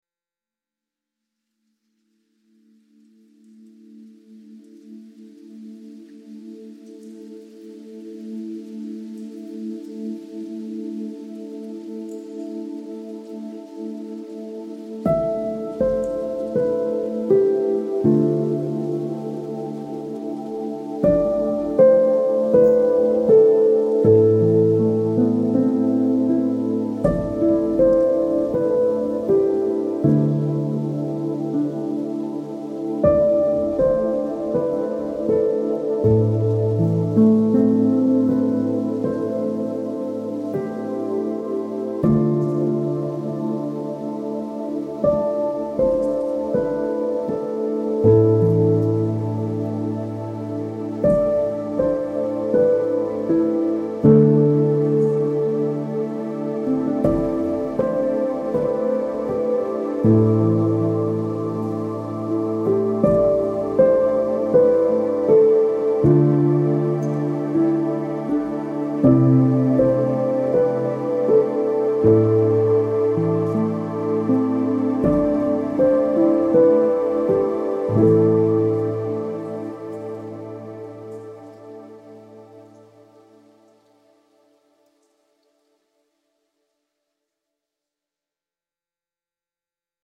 ambient soundscape with field recordings, gentle piano and soft pads